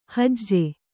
♪ hajje